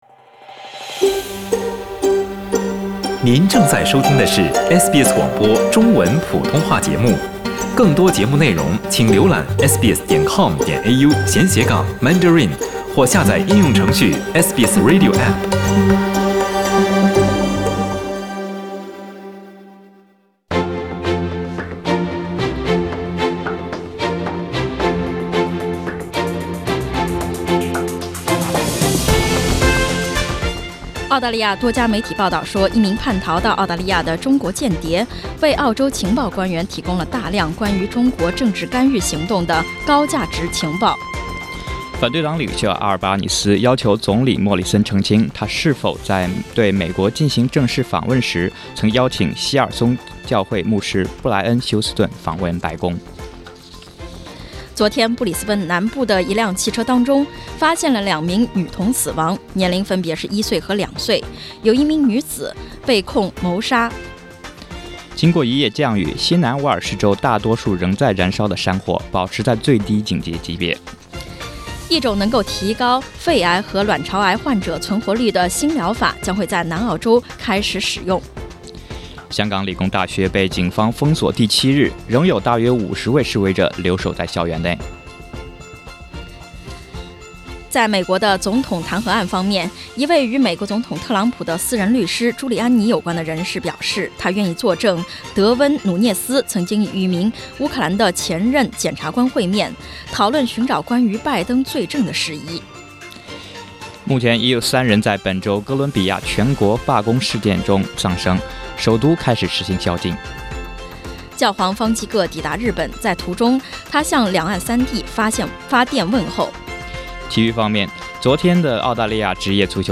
SBS早新闻（11月24日）